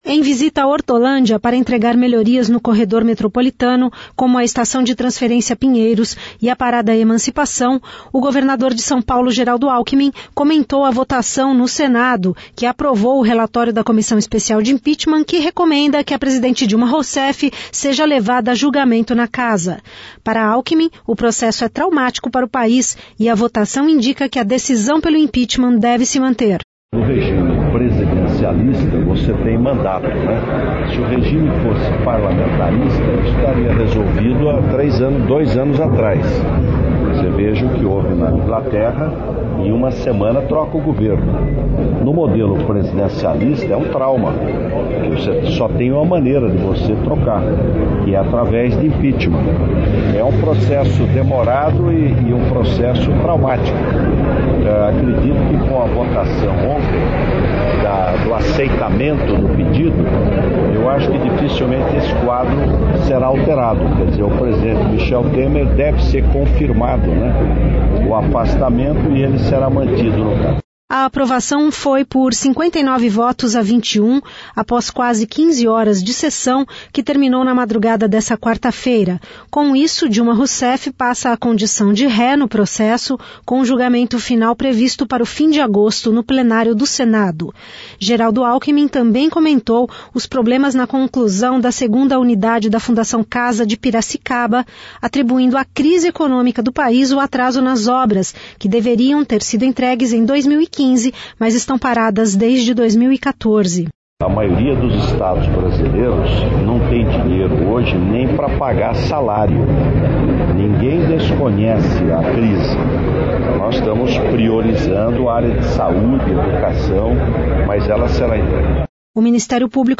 Em visita à Hortolândia para entregar melhorias no corredor metropolitano, como a Estação de Transferência Pinheiros e Parada Emancipação, o governador Geraldo Alckmin comentou a votação no Senado, que aprovou o relatório da comissão especial de impeachment que recomenda que a presidente Dilma Rousseff seja levada a julgamento na casa.